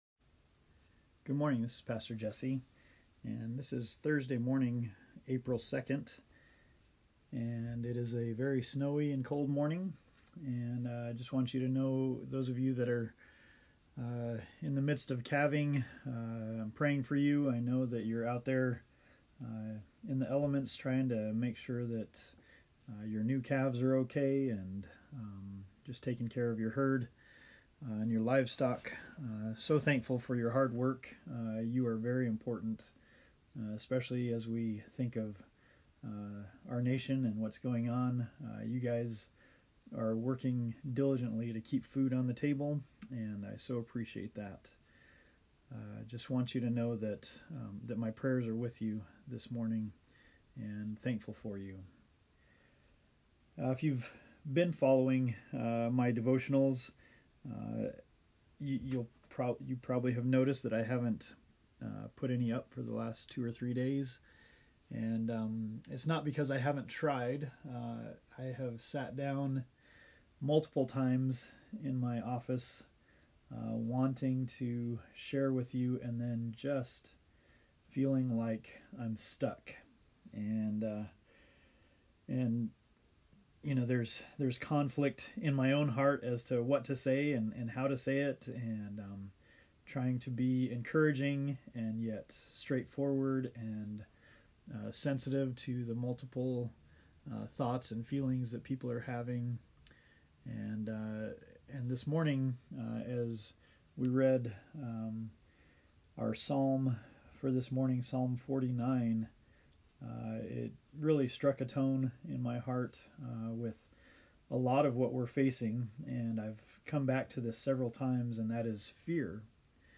God is Sovereign and Good # 11 (A Devotion in Light of COVID-19)
Preacher